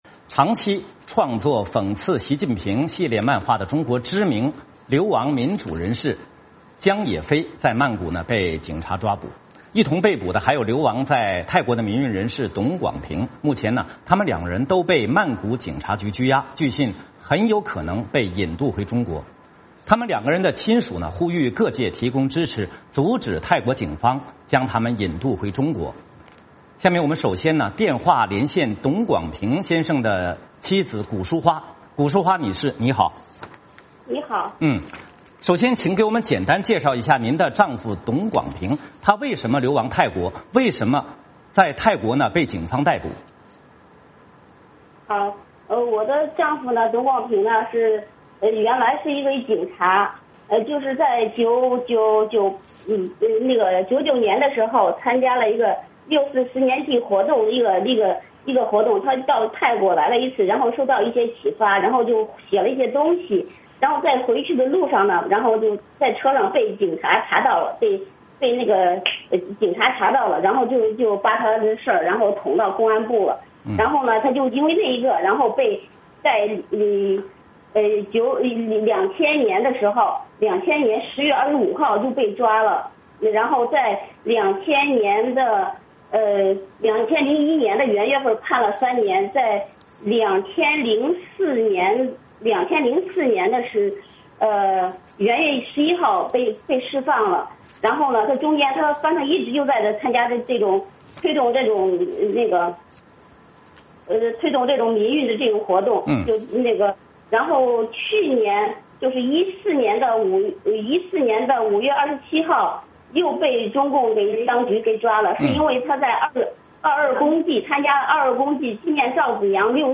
VOA连线